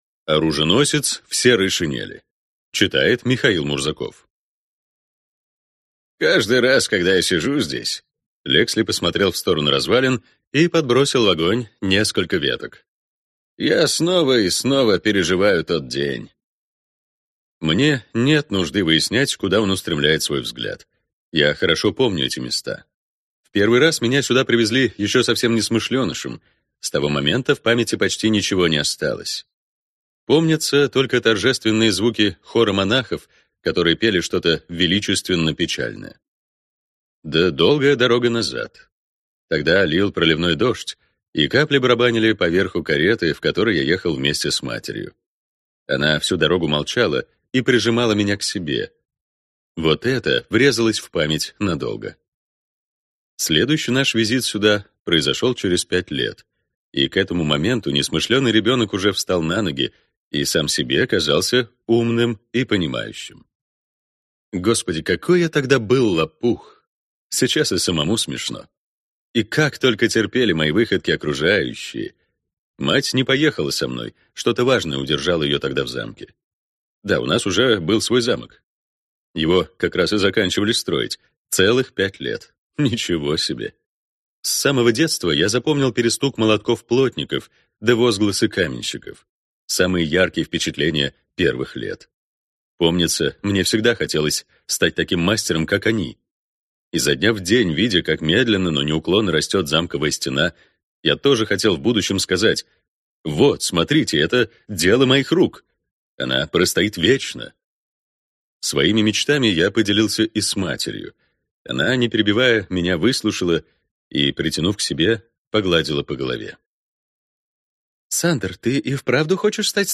Аудиокнига Оруженосец в серой шинели | Библиотека аудиокниг